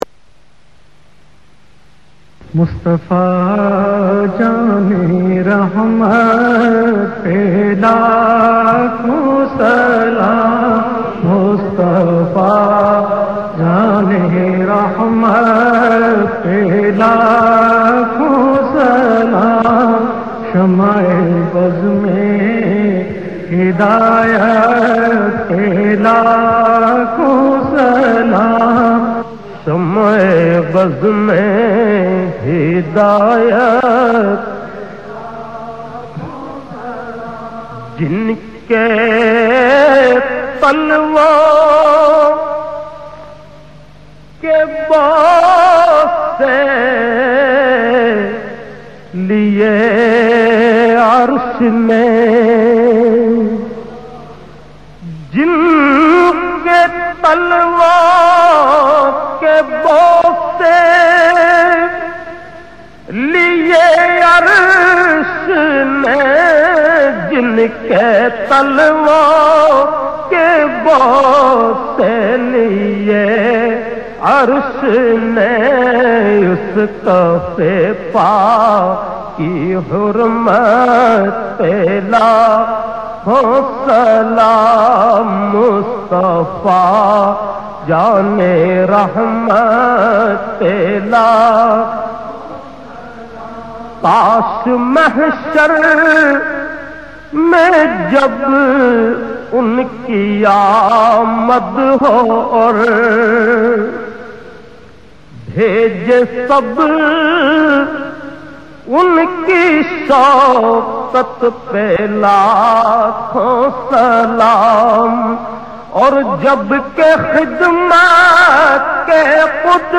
Beautifull Naat
in best audio quality